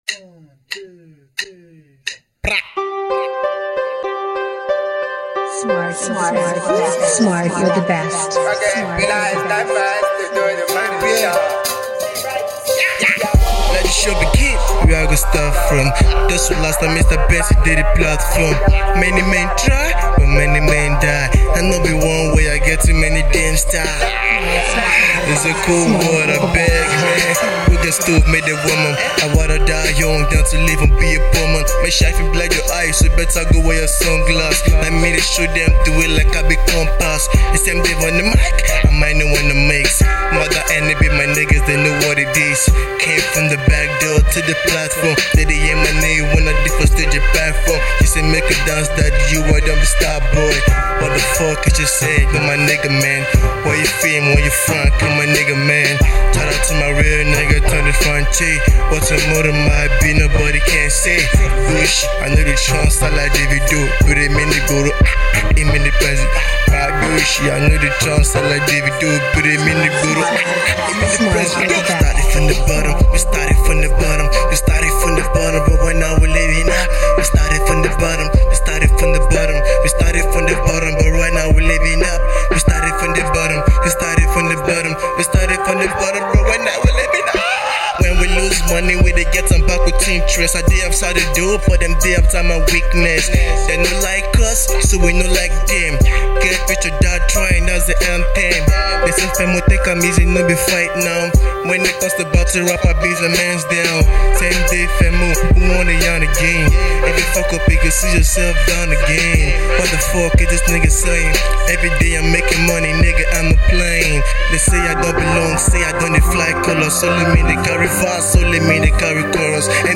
Hip pop, Afropop
Crazy Rap jam and anthem for the Streets